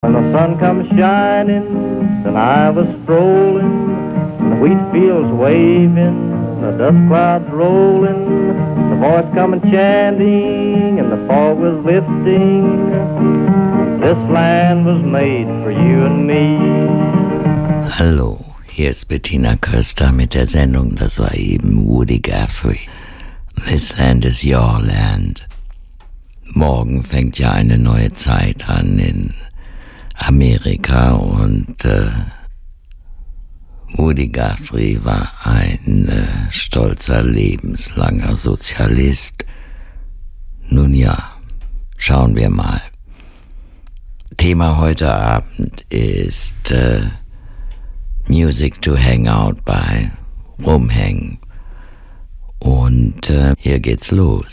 Die ist heute noch aktiv, zum Beispiel bei radioeins und spielt zwar furchtbare Musik, hat aber mittlerweile ein vollkommen unglaubliches, dunkles Timbre bekommen, das mich zuerst an einen Übertragungsfehler glauben ließ.